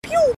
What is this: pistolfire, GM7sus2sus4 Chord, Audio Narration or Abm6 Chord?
pistolfire